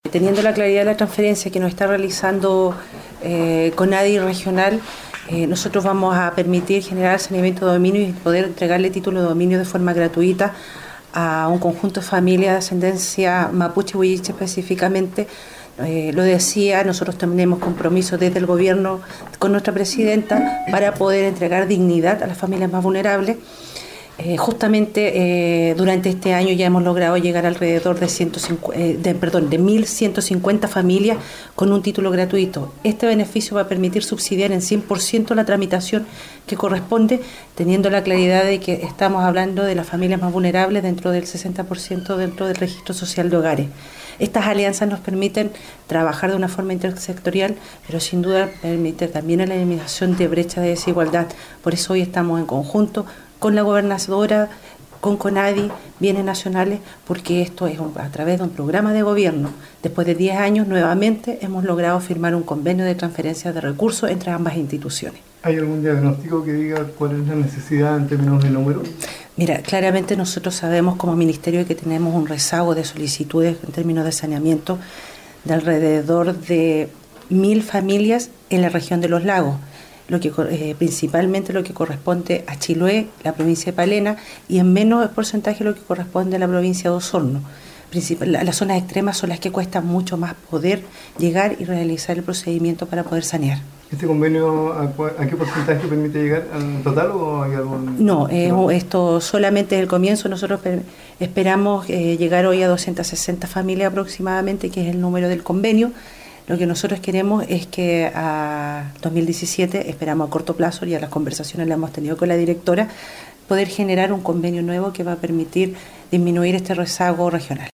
Habla Seremi de Bienes Nacionales Adriana Maldonado 260 familias de la Región regularizarán sus tierras a través de convenio firmado entre Conadi y Bienes Nacionales.